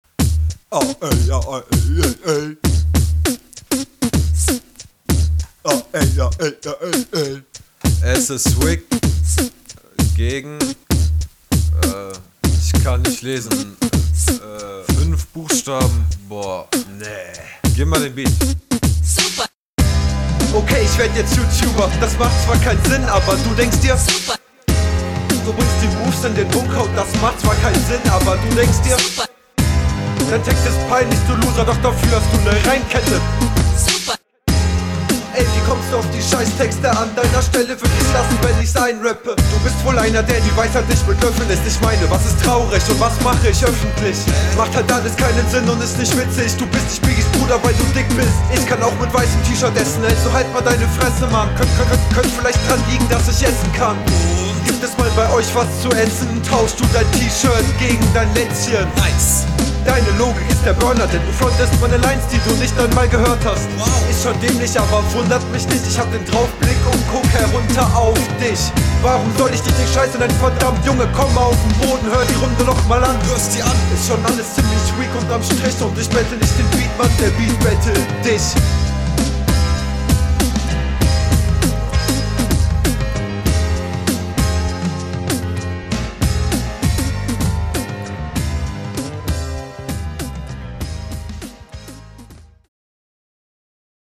Intro kommt eigenartig.